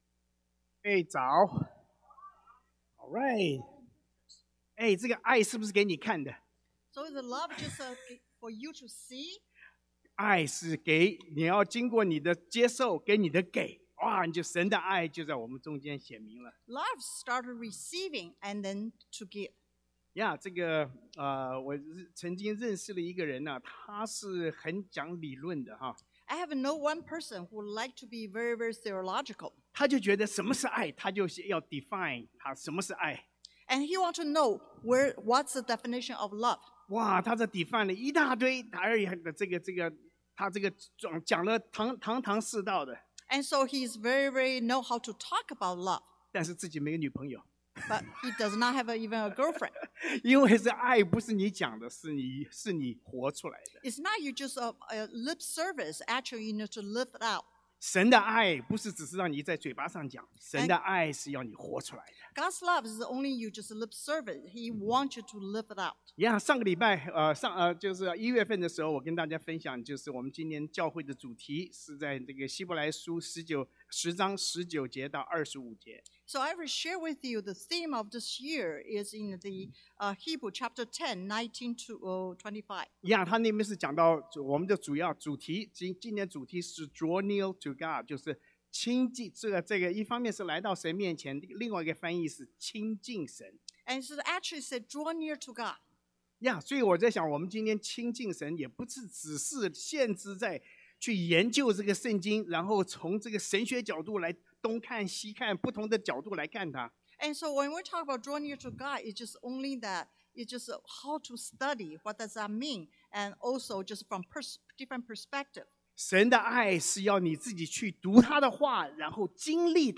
Passage: 希伯來書 Hebrew 10: 22-25 Service Type: Sunday AM